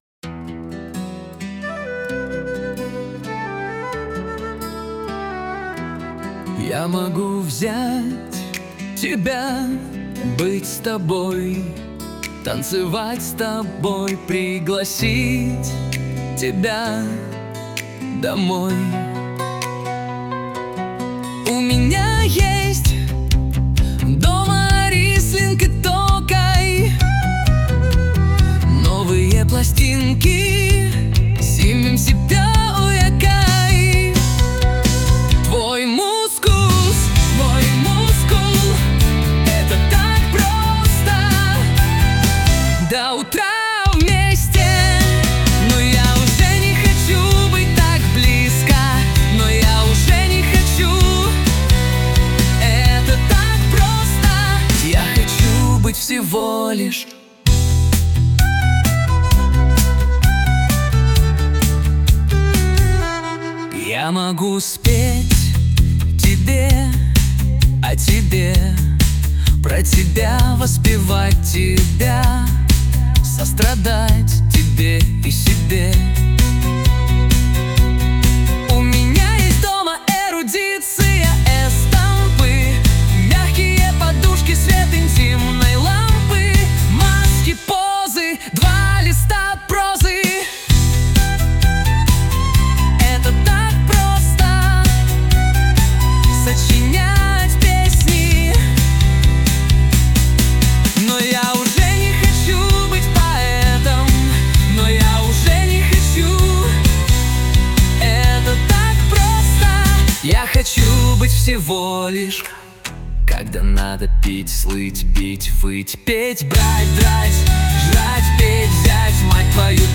RUS, Romantic, Lyric, Rock, Indie | 03.04.2025 20:48